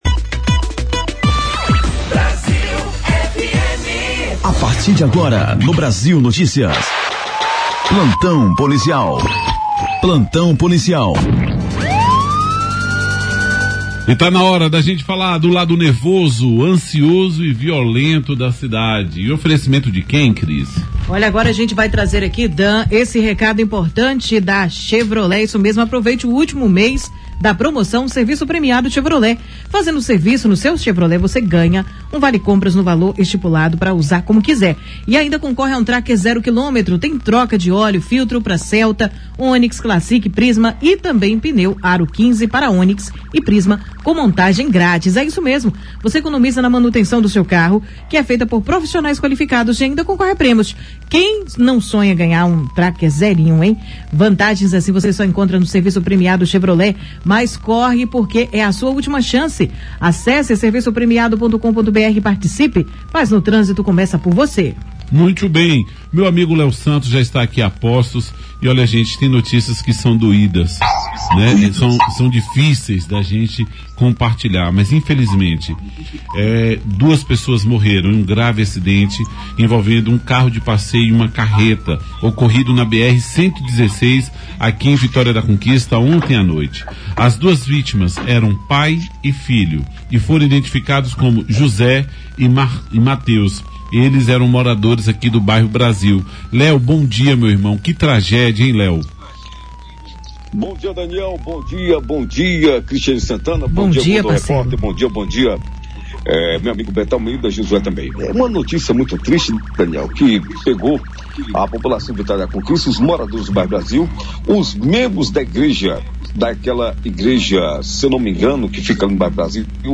Comentário Político | em reunião tensa, Sheila define Ivanzinho à Presidência da Câmara de Vitória da Conquista